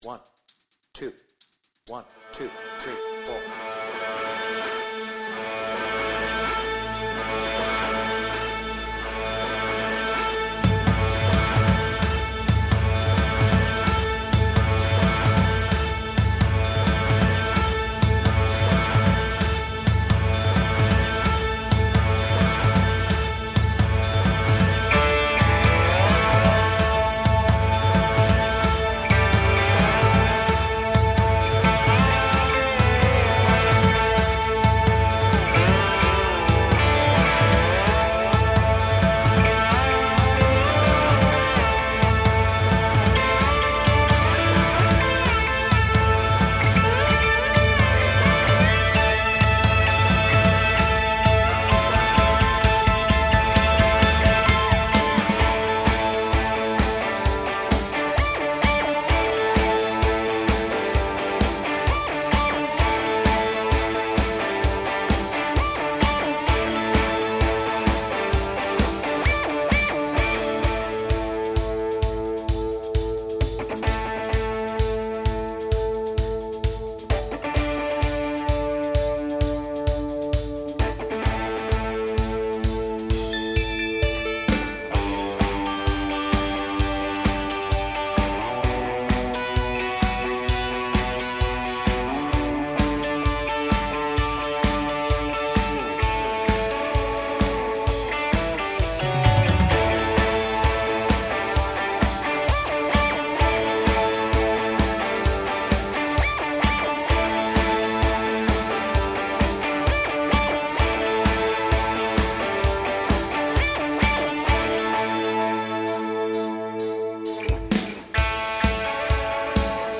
Without vocals